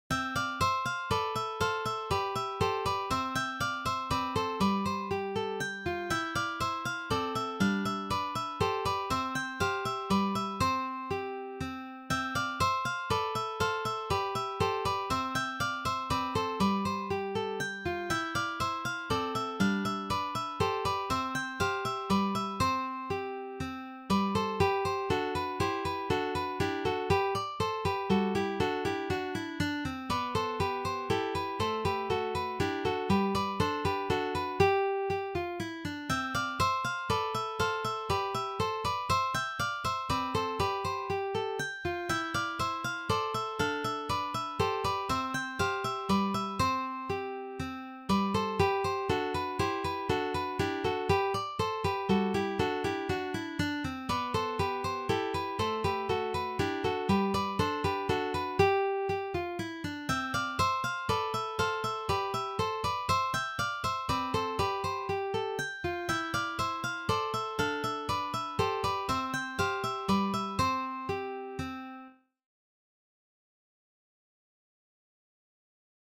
arranged for three guitars